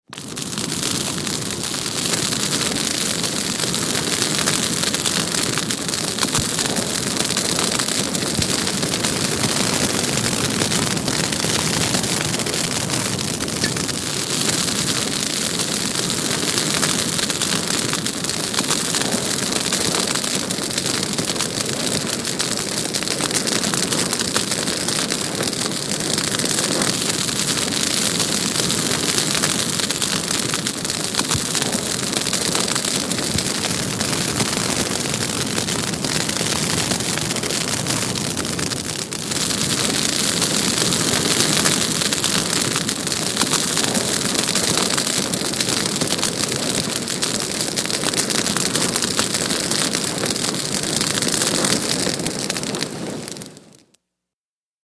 BrushFireHvyCrackl PE700101
FIRE BRUSH FIRE: EXT: Heavy brush fire, close up crackles, audible fire convection.. Fire Burn.